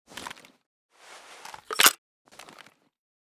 ak74_grenload.ogg